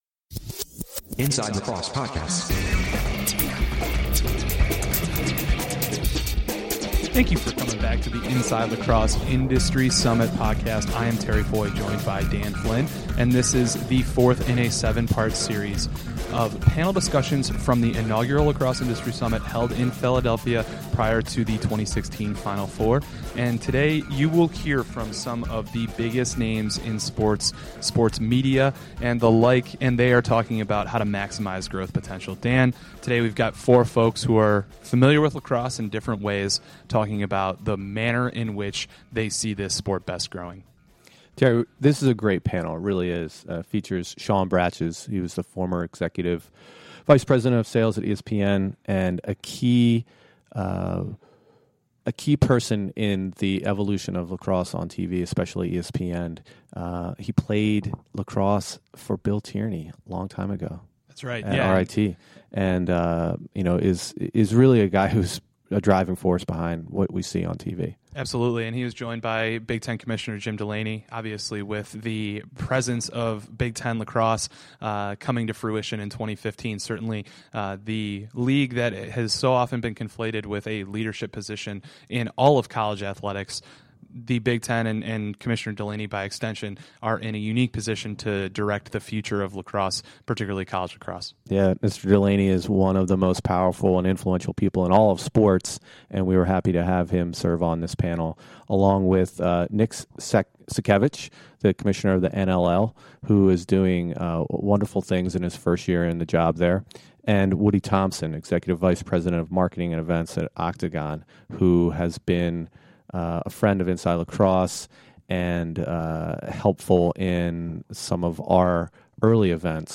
A star-studded panel discusses ways to deliver on lacrosse’s promising growth potential, along with the challenges in the way.